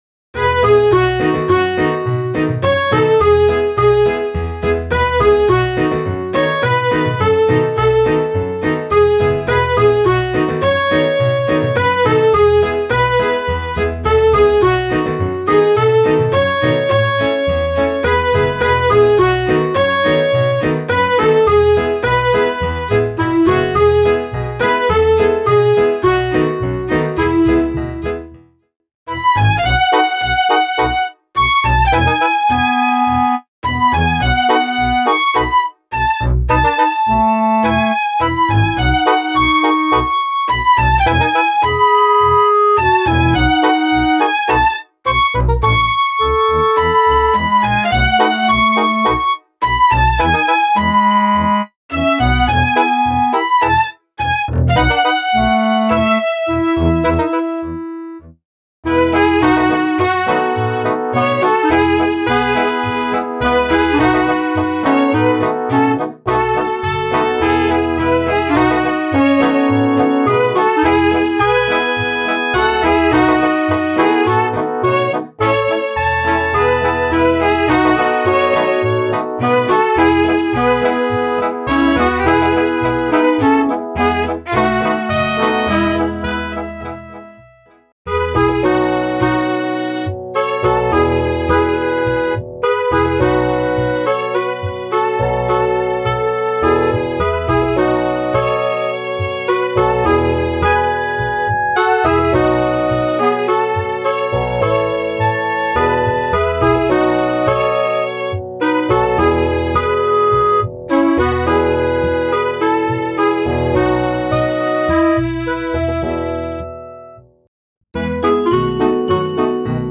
Klaviersatz